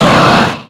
Cri de Latias dans Pokémon X et Y.